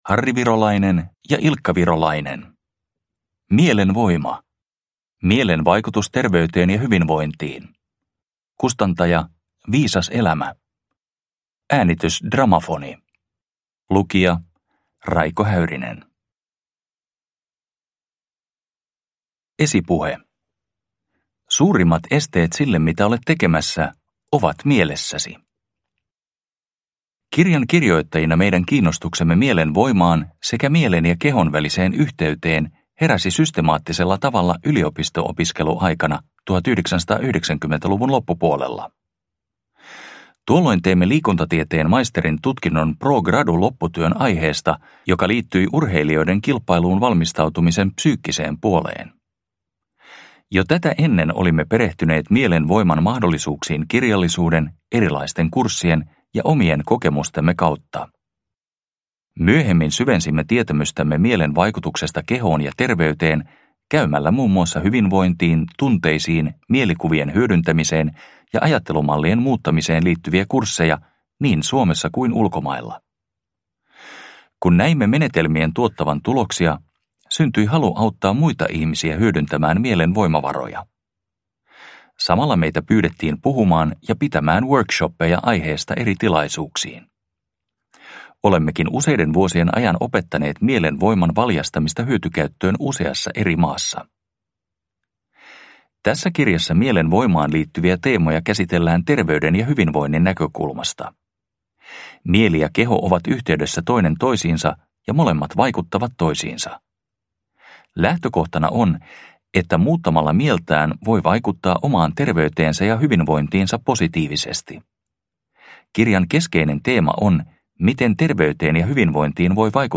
Mielen voima – Ljudbok – Laddas ner